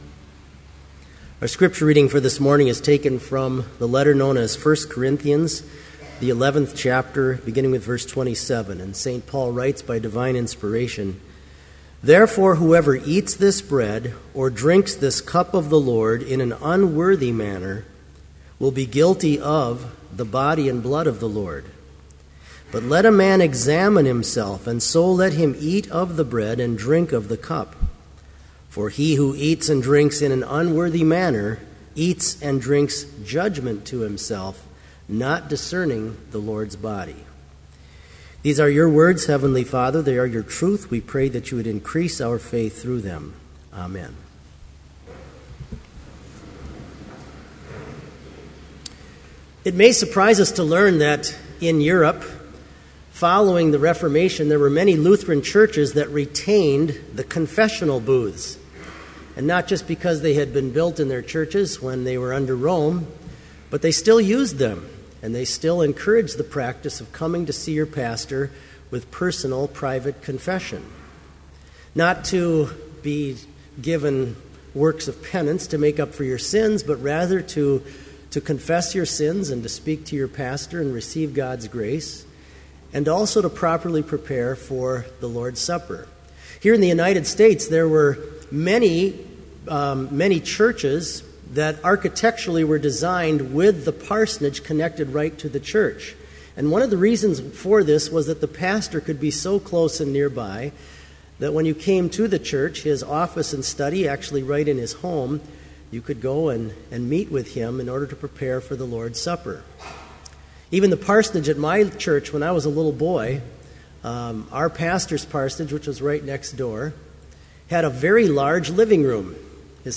Complete Service
• Prelude
• Homily
This Chapel Service was held in Trinity Chapel at Bethany Lutheran College on Friday, October 12, 2012, at 10 a.m. Page and hymn numbers are from the Evangelical Lutheran Hymnary.